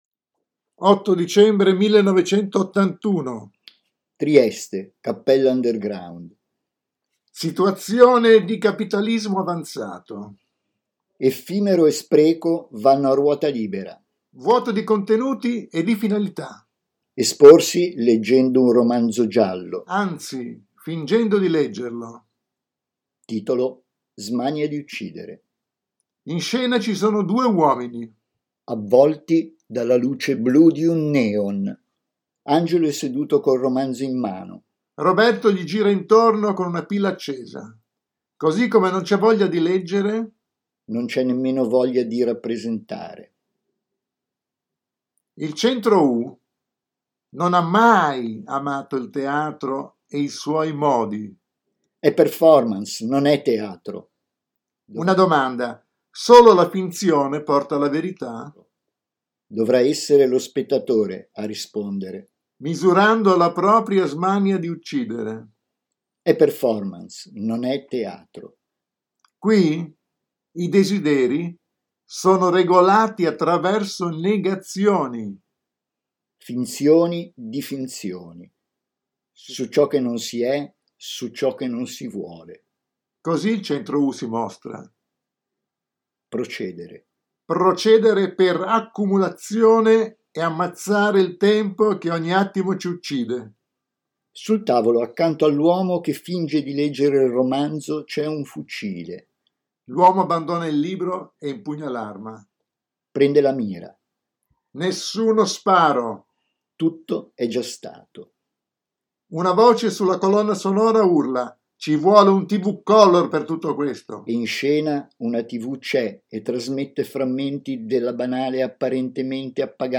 intervento sonoro
realizzato in occasione della mostra ‘Territori della performance’, al Museo MAXXI, Roma 2023